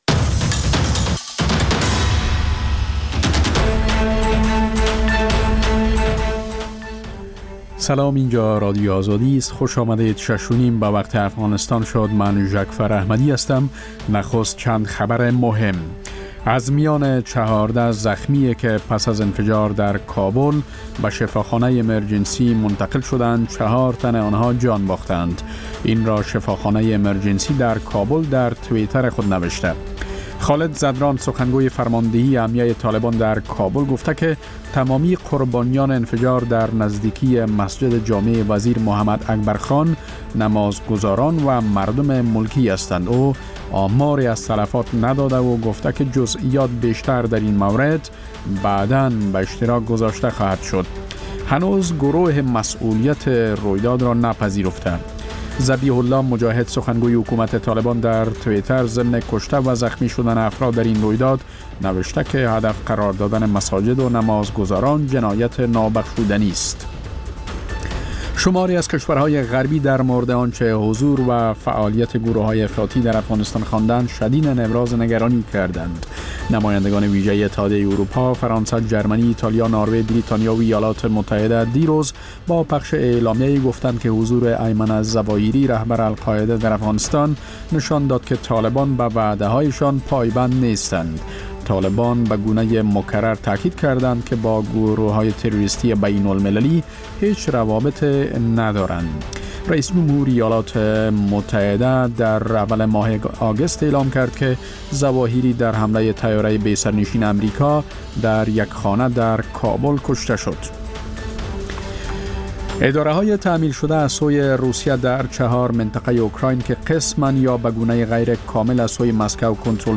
مجله خبری شامگاهی
پخش زنده - رادیو آزادی